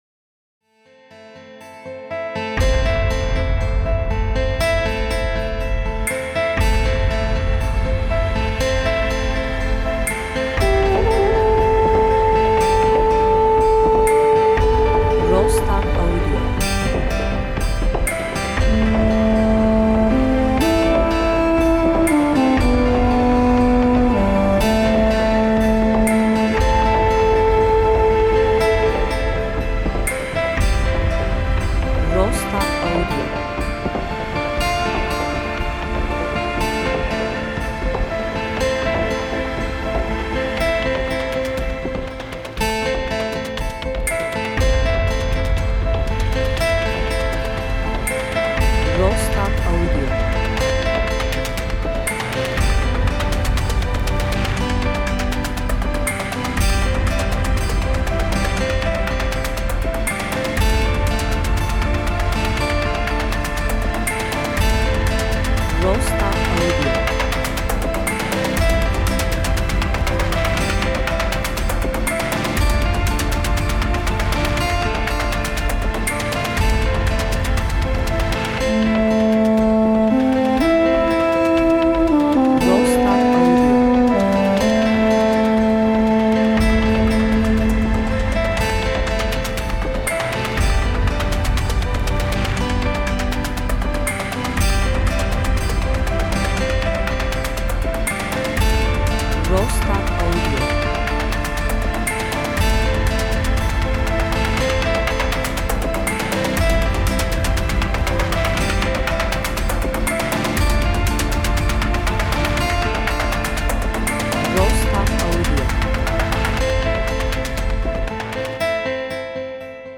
entrümantal
epik